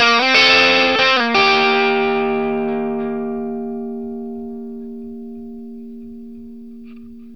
BLUESY1 C 60.wav